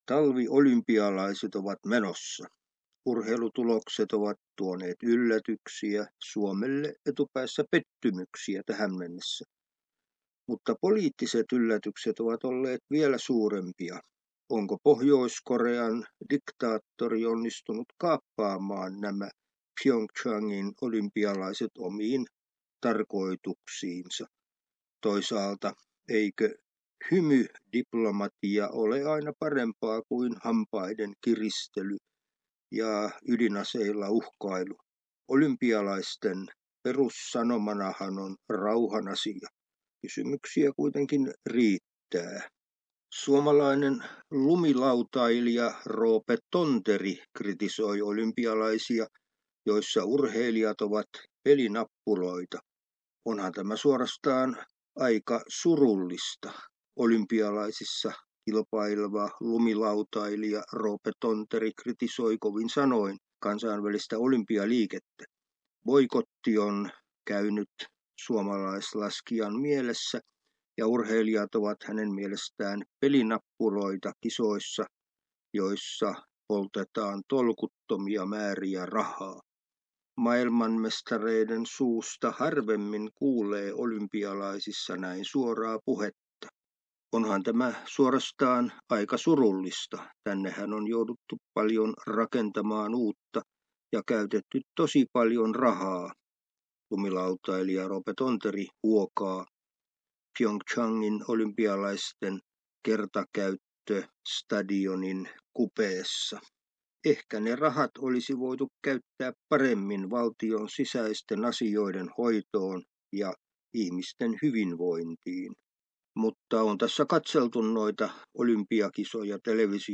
urheilu- ja kulttuuriraportti